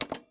pgs/Assets/Audio/Sci-Fi Sounds/Mechanical/Device Toggle 11.wav at 7452e70b8c5ad2f7daae623e1a952eb18c9caab4
Device Toggle 11.wav